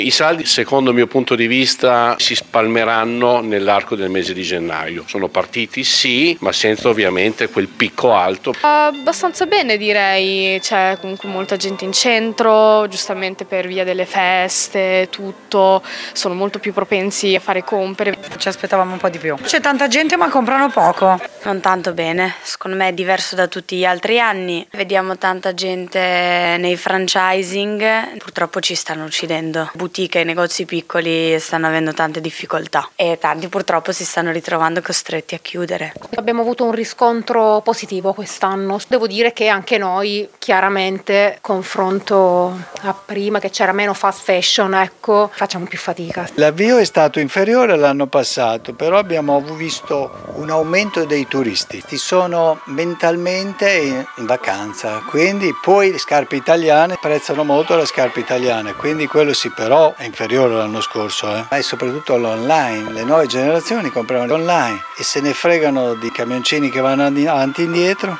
Qui sotto le interviste ai commercianti del centro